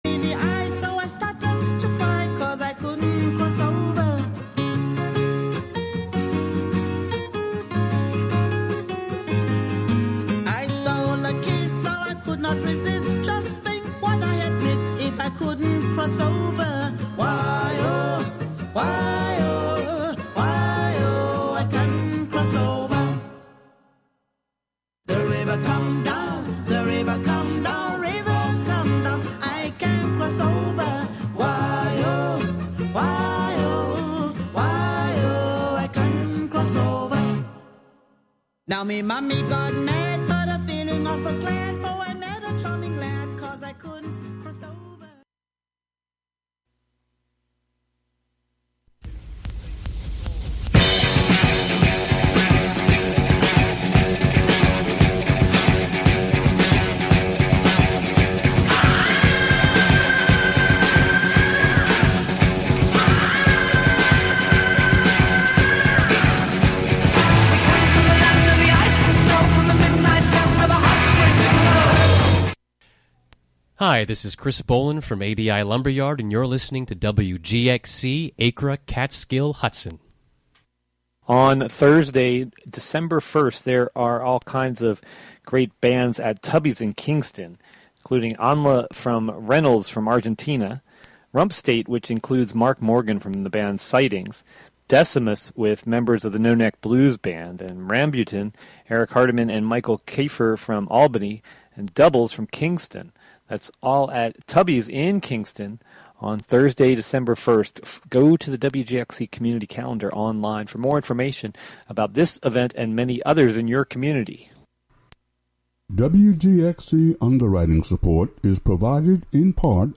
A broadcast featuring music